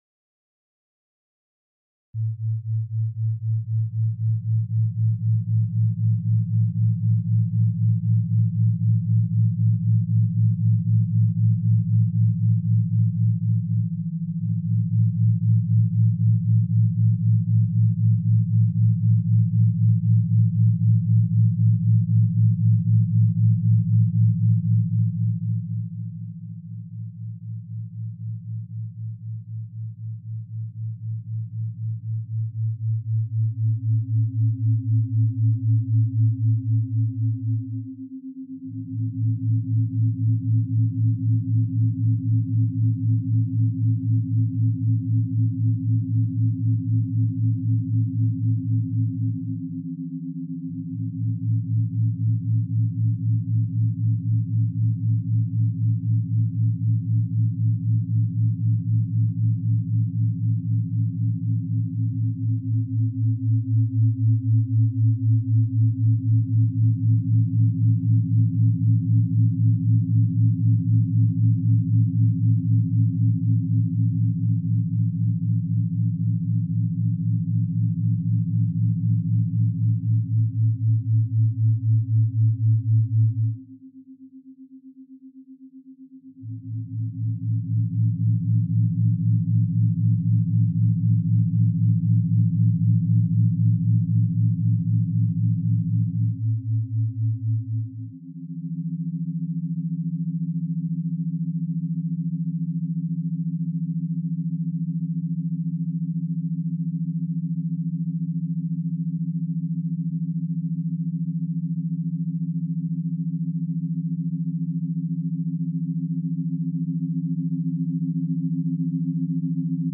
Ce chant vibratoire active la glande pinéale
CHANTS VIBRATOIRES